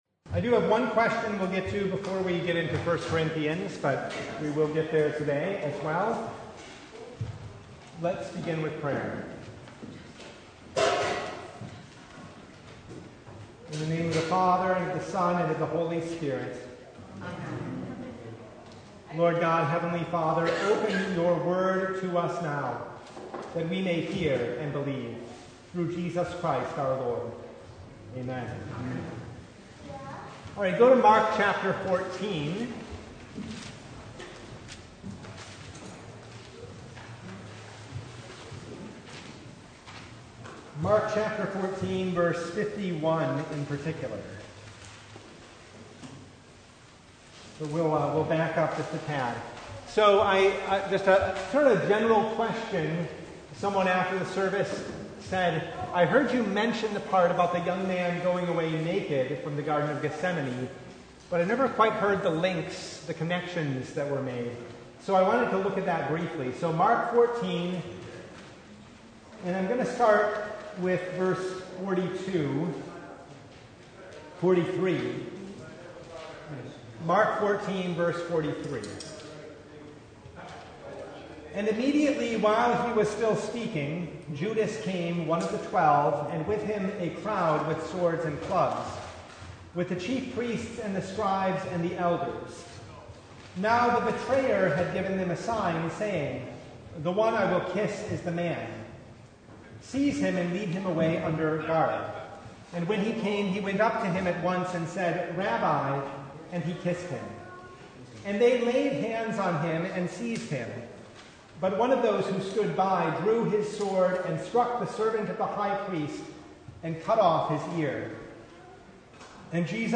Service Type: Bible Hour Topics: Bible Study